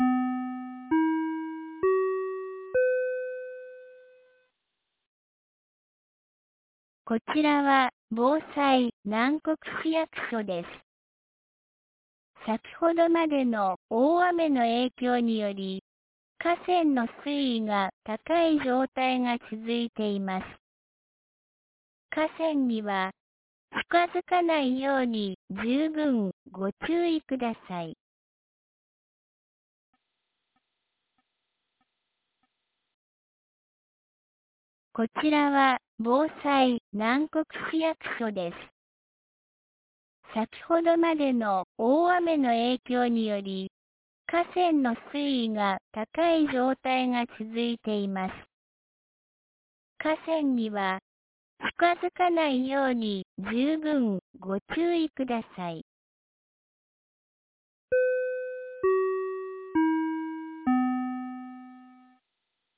2024年05月28日 15時26分に、南国市より放送がありました。